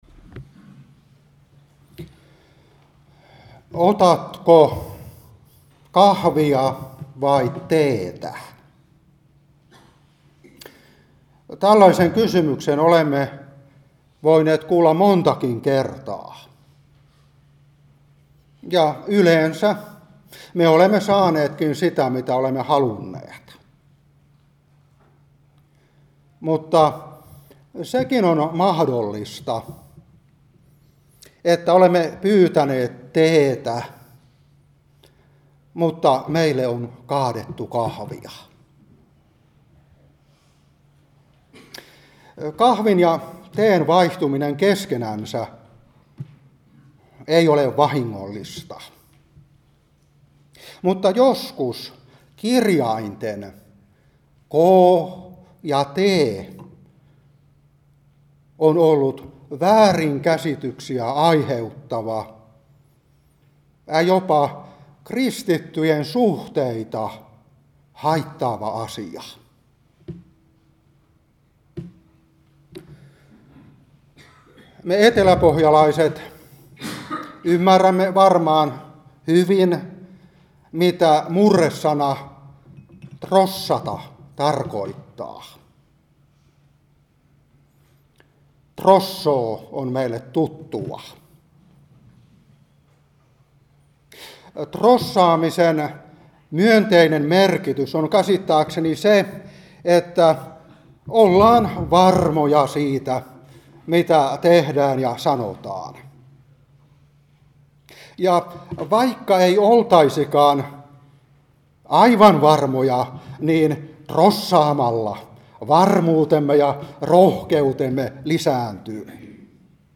Seurapuhe 2023-9. 1.Tim.1:15.